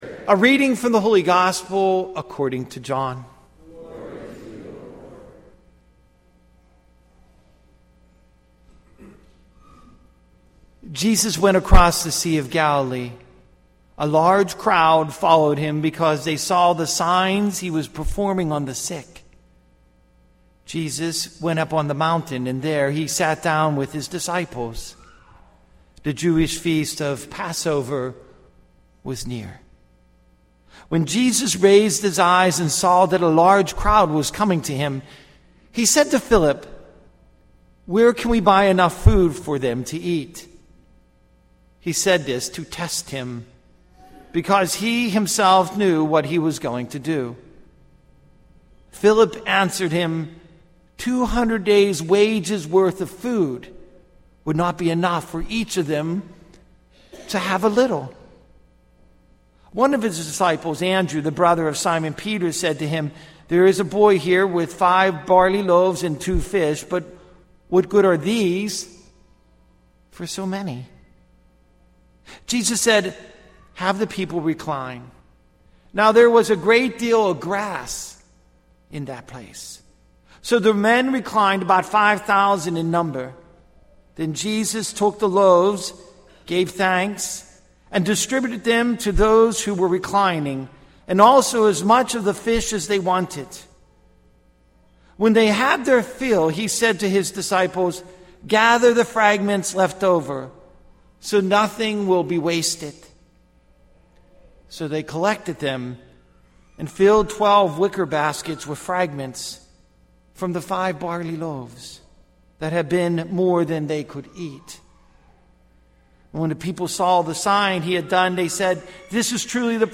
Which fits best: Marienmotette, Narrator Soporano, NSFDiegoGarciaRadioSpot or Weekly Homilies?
Weekly Homilies